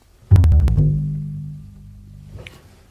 Koto
th koto is a plink type instrument. give it a listen.
The_beautiful_koto.mp3